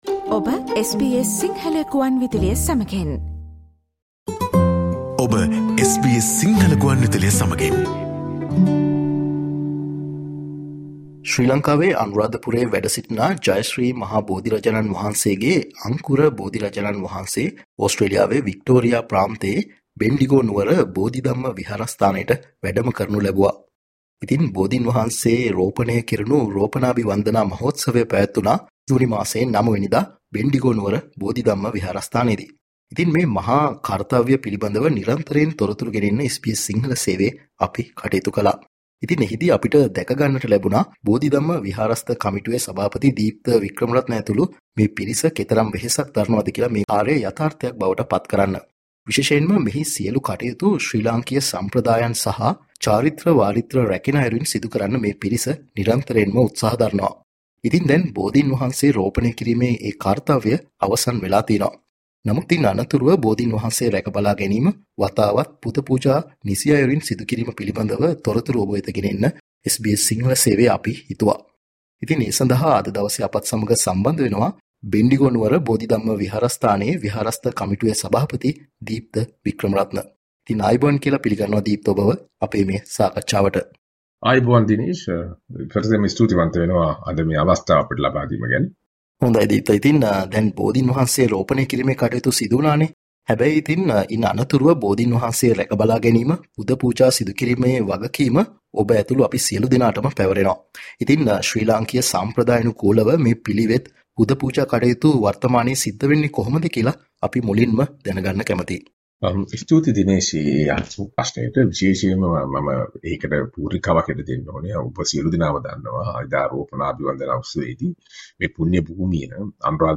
Listen to SBS Sinhala discussion about the bodhi dhamma temple in Bendigo that houses a direct sapling of Sri Mahabodhi of Anuradhapura, Sri Lanka.